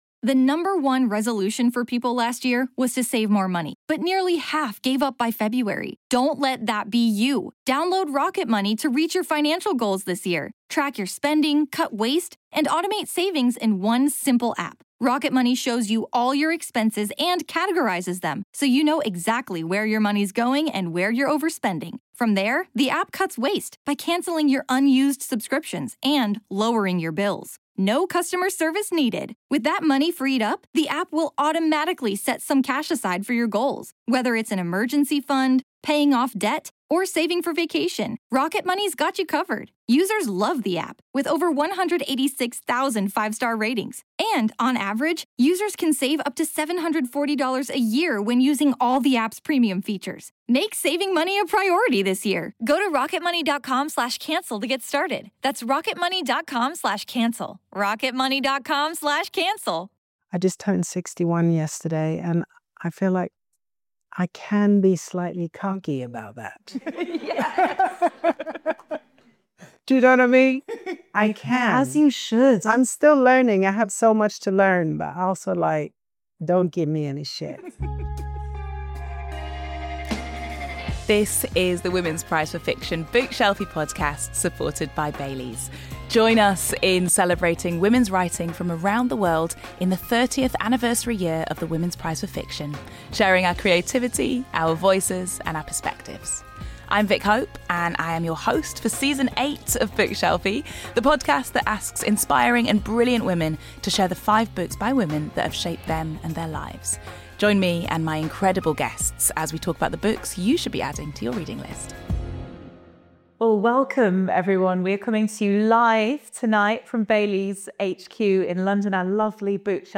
Recorded live at Bailey’s HQ in London, singer-songwriter, rapper, producer and Women’s Prize 2025 longlisted author Neneh Cherry discusses her career, the power of women and the process of writing her deeply personal memoir, A Thousand Threads.